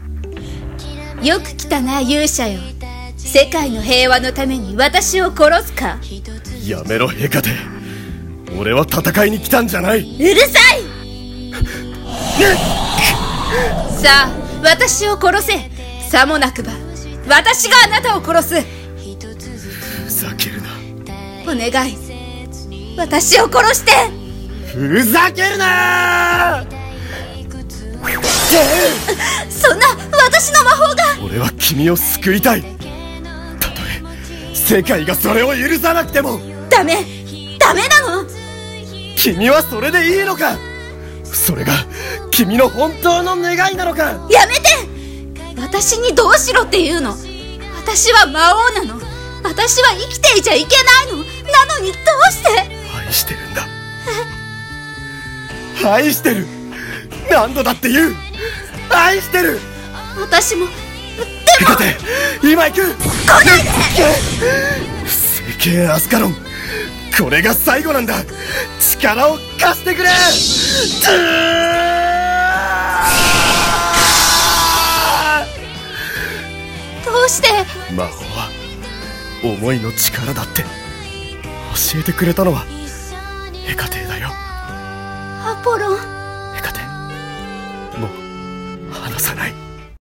【声劇】勇者を愛した魔王【二人用】